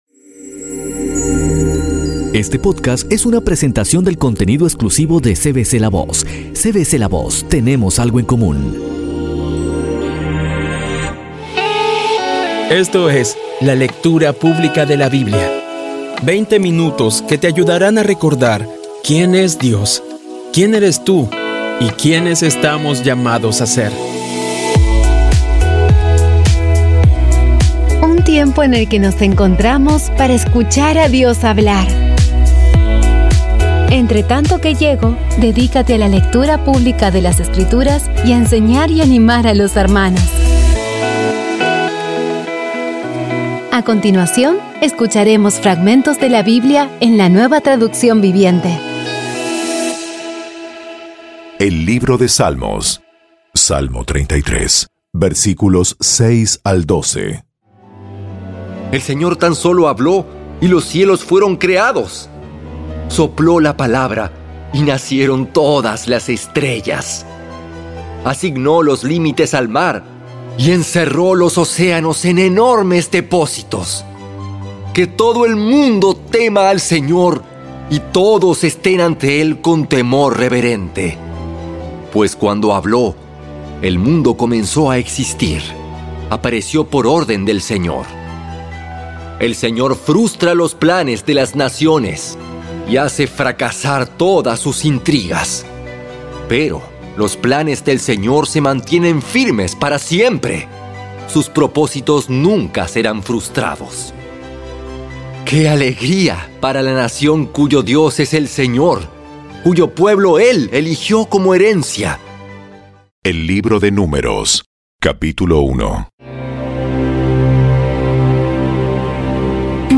Audio Biblia Dramatizada por CVCLAVOZ / Audio Biblia Dramatizada Episodio 64
Poco a poco y con las maravillosas voces actuadas de los protagonistas vas degustando las palabras de esa guía que Dios nos dio.